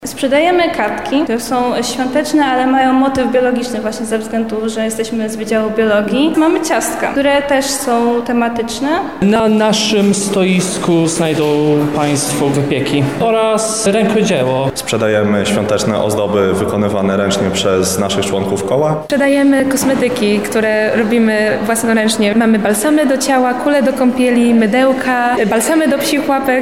16 grudnia odbył się Akademicki Jarmark Studencki na Wydziale Ekonomicznym Uniwersytetu Marii Curie Skłodowskiej.
Zapytaliśmy również uczestników wydarzenia co można znaleźć na ich stoiskach: